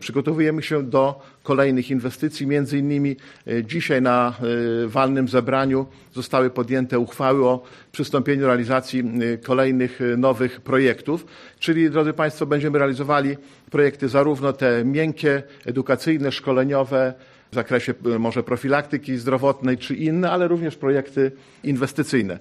O działaniach Forum mówił przewodniczący zebrania, starosta łomżyński, Lech Marek Szabłowski: